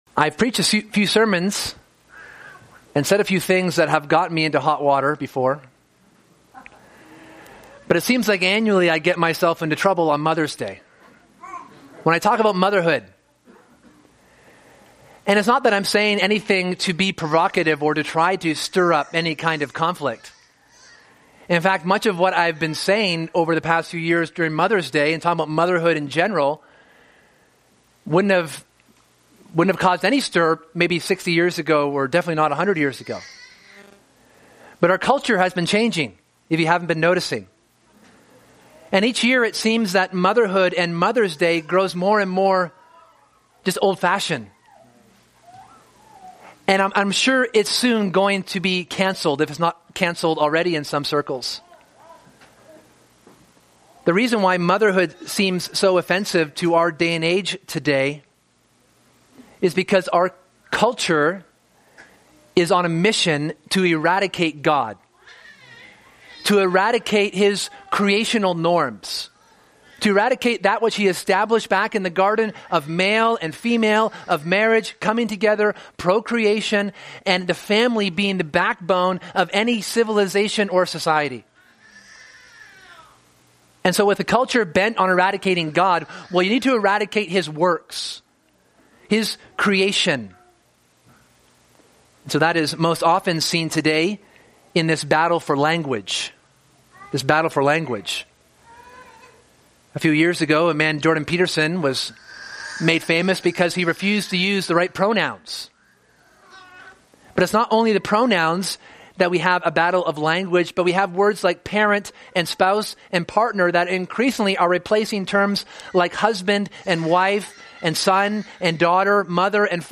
This Sunday we take some time to celebrate God’s gift of motherhood by considering the inspired words of a mother to her son. We see here such encouragement and beauty in God’s gift of motherhood.